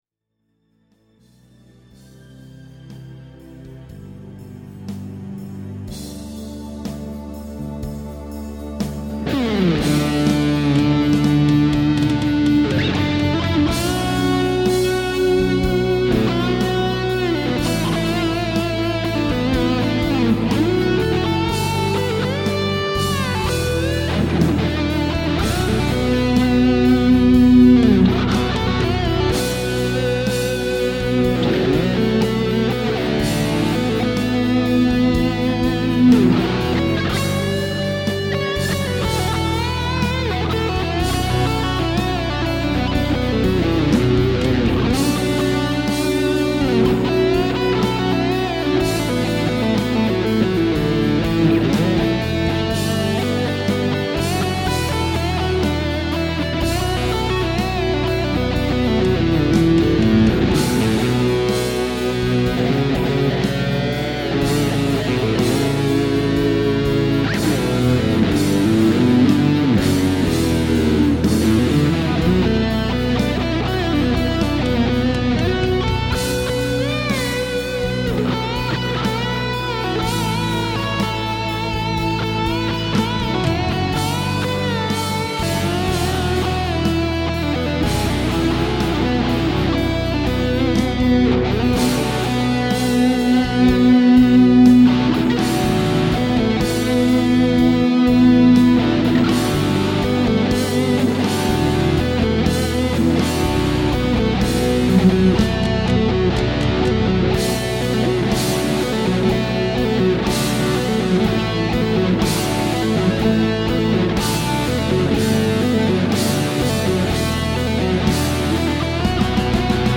Innostuin tosta kirjasta jo etukäteen niin paljon että piti äänittää vähän jammailua Bm - A - G - Em -Bm -sointukiertoon: